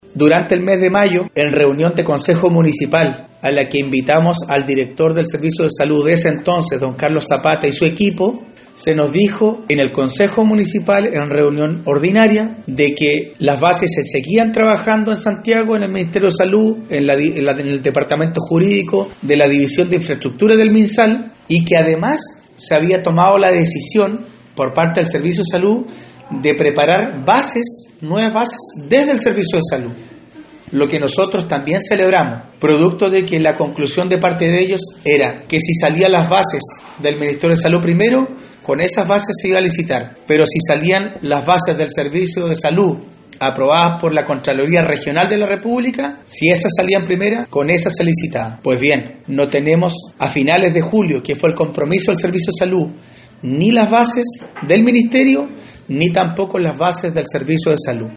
Marcos Vargas, Alcalde de la comuna de Queilen, dijo que desde mayo que no se ha avanzado en entregar bases para una licitación, ni por el Ministerio ni por el Servicio de Salud, el que en ese mismo mes en reunión de consejo, empeño su palabra que así será, hecho que no ha acontecido.